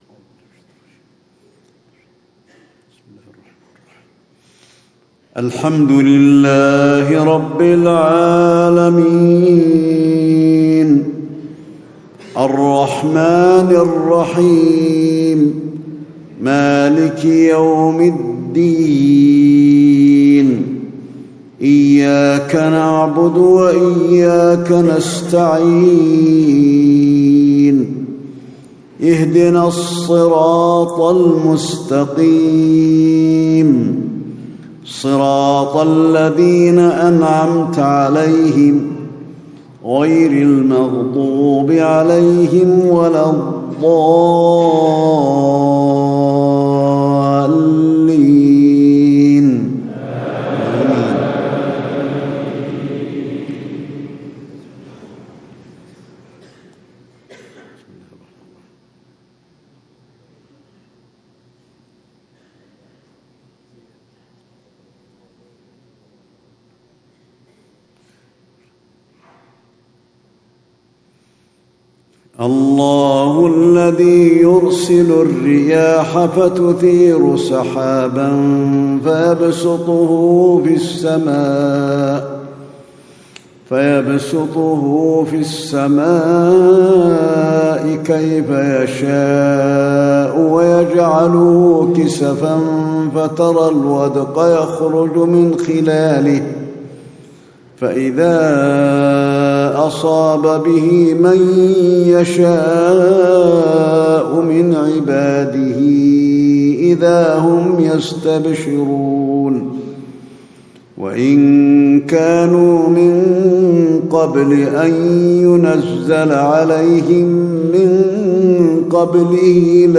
صلاة العشاء 20 صفر 1437هـ خواتيم سورة الروم 48-60 > 1437 🕌 > الفروض - تلاوات الحرمين